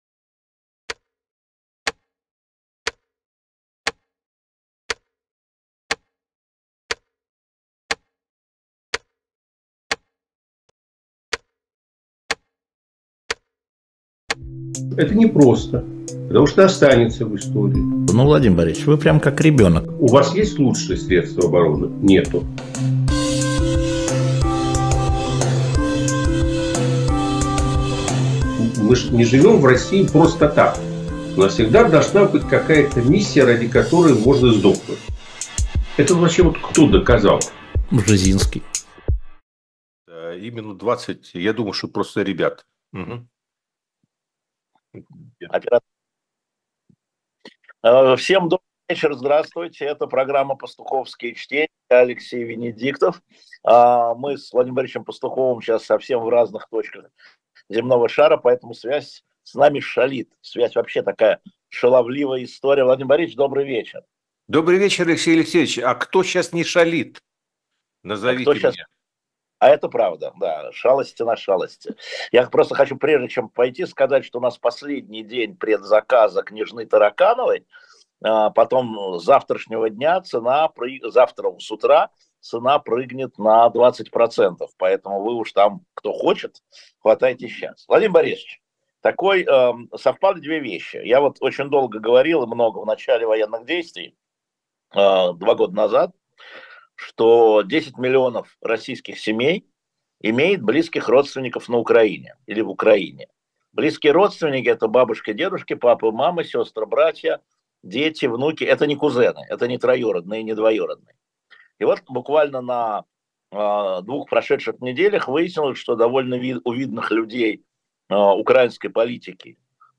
«Пастуховские четверги» Владимир Пастухов политолог Алексей Венедиктов журналист Теперь новая концепция: русские воюют с русскими.